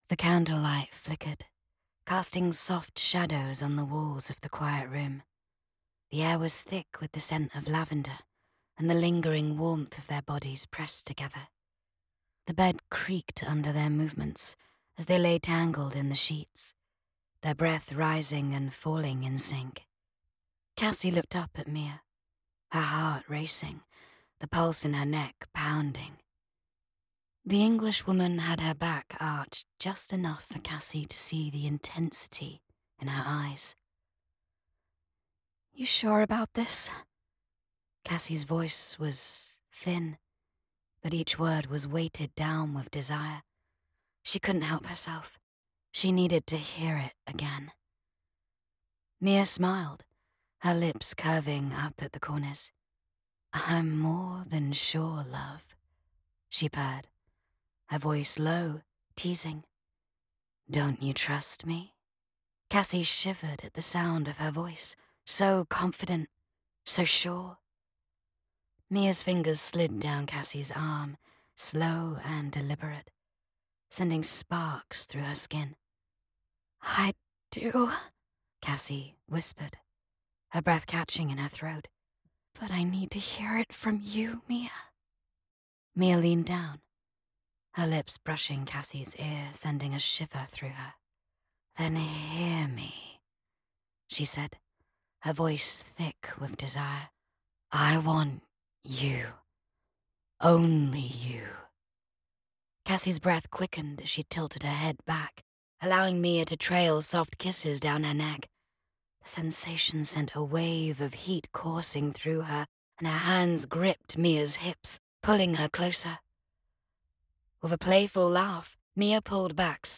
3RD PERSON LGBTQIA+, F/F, NEUTRAL BRITISH, GENERAL AMERICAN
Custom built broadcast quality studio